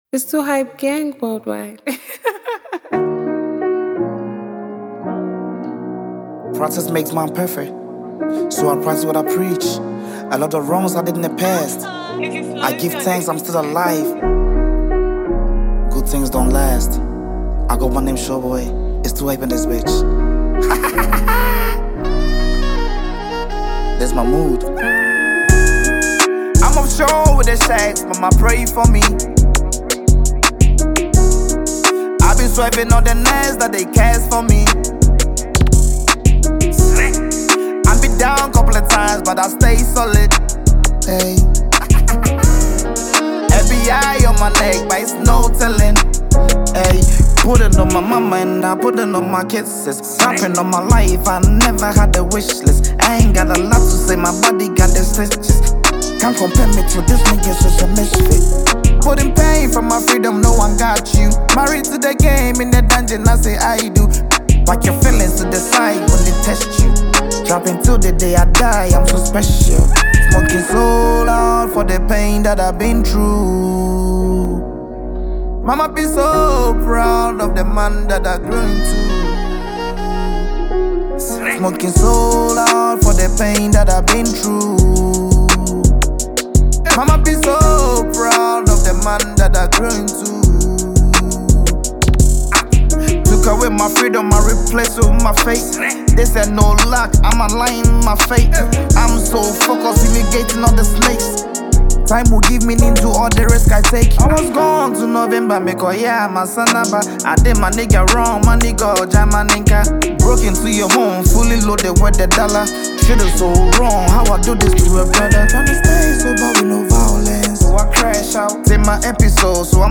smooth and captivating Afrobeat record
calm yet expressive vocal performance
• Genre: Afrobeat